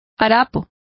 Complete with pronunciation of the translation of tatters.